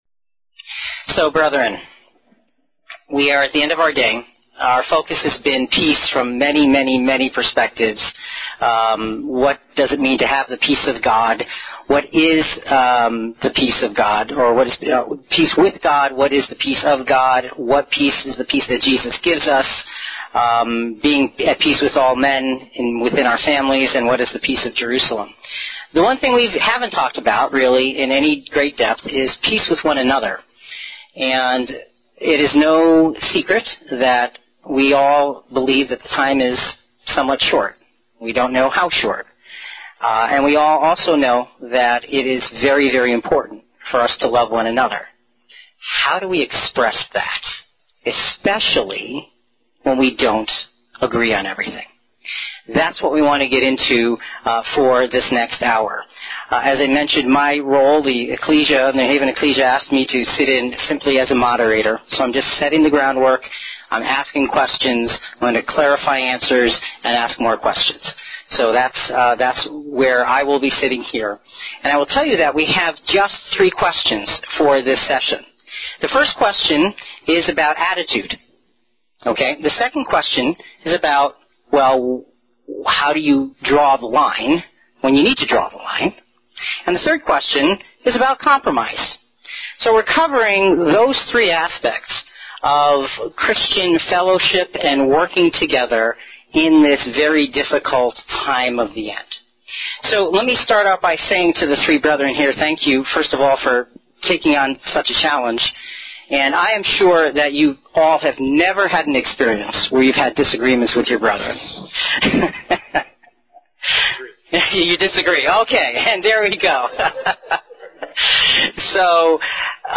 Panel_Discussion_-_Peace_Among_Brethren.mp3